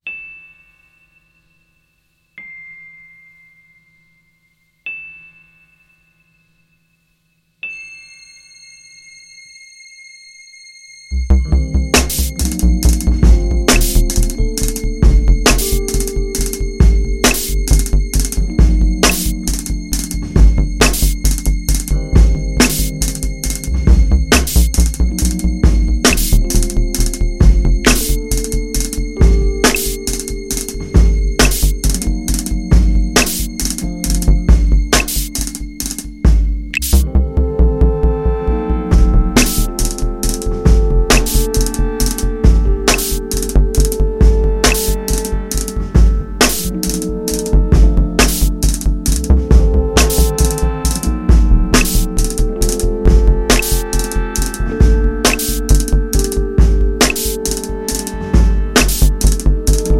drummer